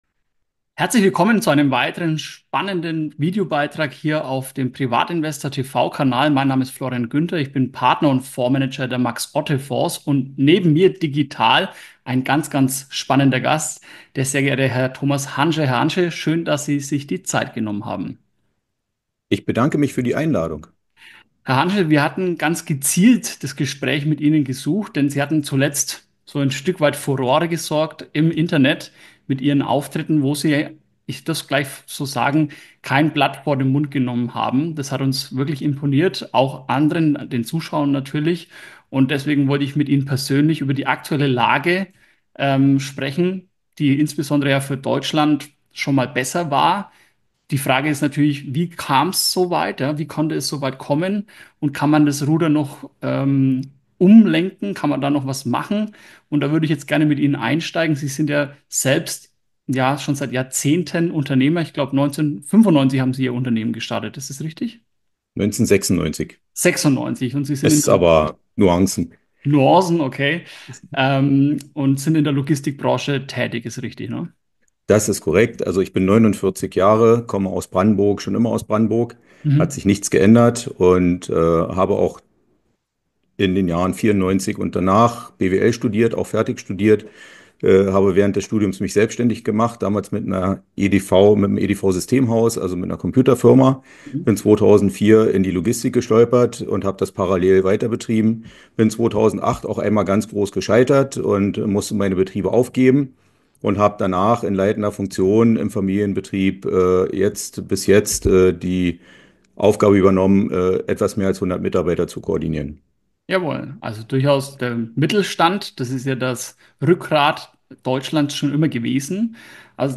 Und gibt es noch Hoffnung für den Wirtschaftsstandort Deutschland? Ein spannendes Interview über die wahren Ursachen der aktuellen Wirtschaftskrise und mögliche Lösungswege.